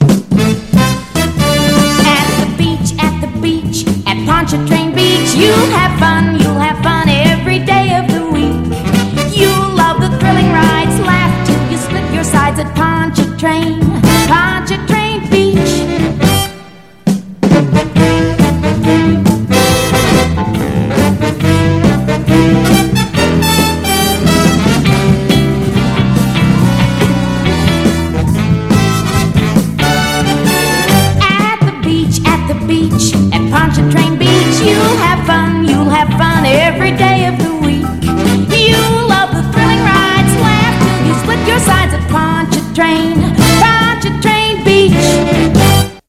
Pontchartrain Beach radio spot - WTIX 1962  (1:02)